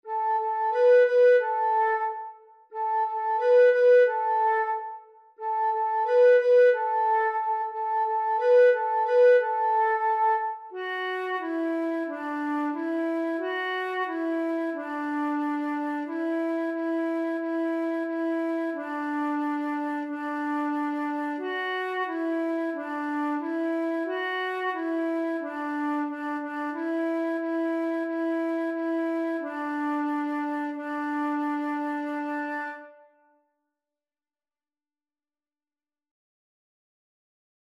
Lager, in G
dit liedje is pentatonisch